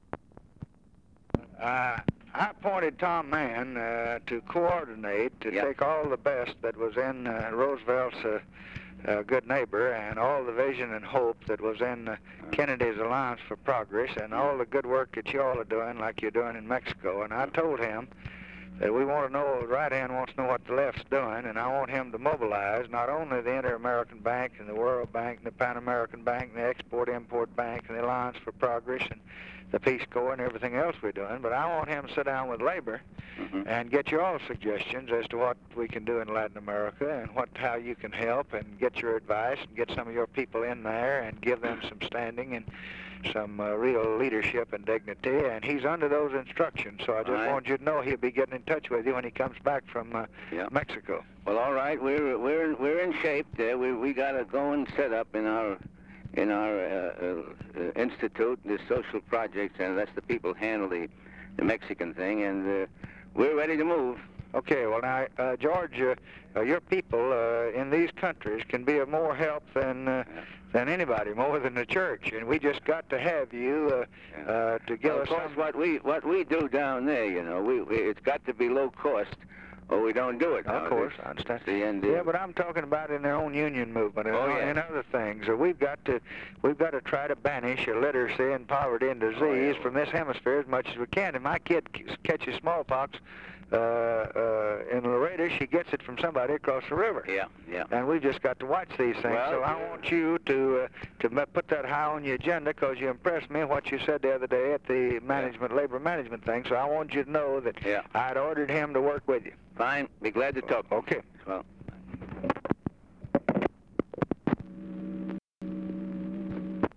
Conversation with GEORGE MEANY, December 16, 1963
Secret White House Tapes